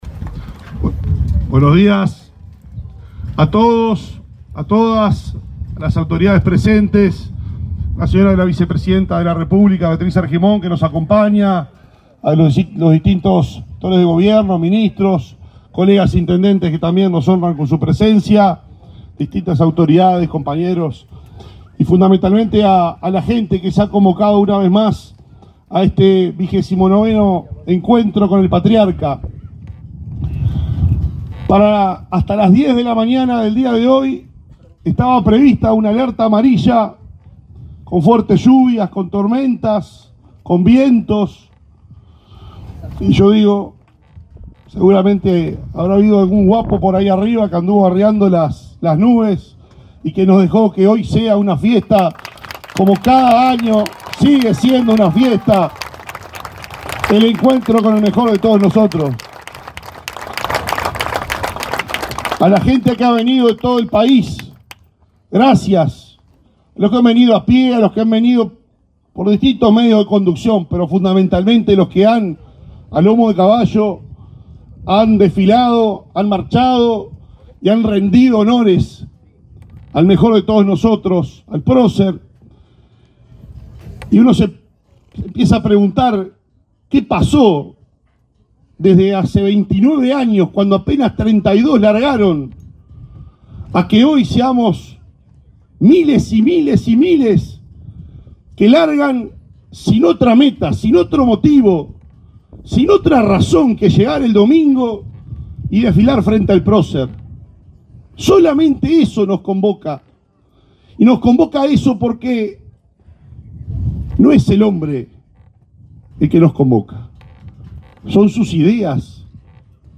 Palabras del intendente de Paysandú, Nicolás Olivera 24/09/2023 Compartir Facebook X Copiar enlace WhatsApp LinkedIn El intendente de Paysandú, Nicolás Olivera, hizo uso de la palabra durante el Encuentro con el Patriarca, realizado este domingo 24 en la Meseta de Artigas, en ese departamento.